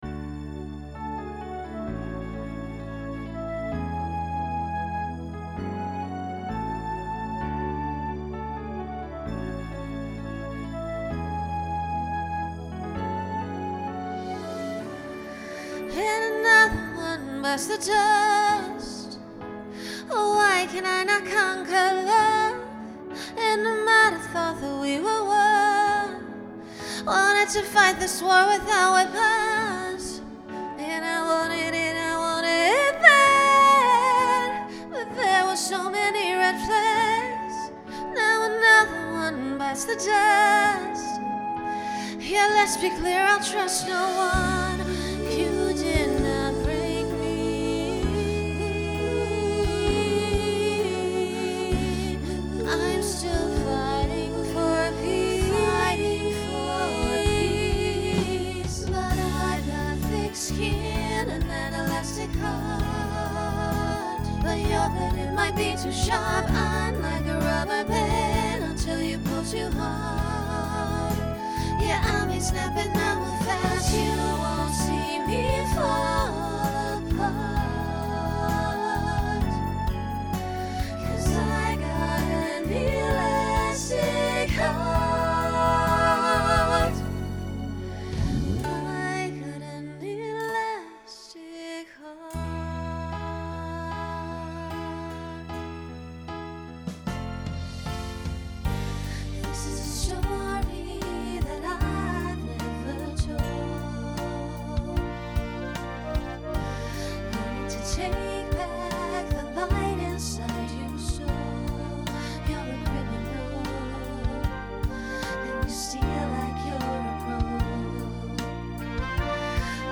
Genre Pop/Dance
Function Ballad Voicing SSA